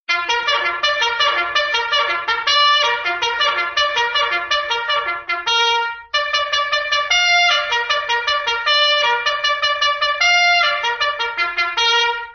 army-bugle-tone_24991.mp3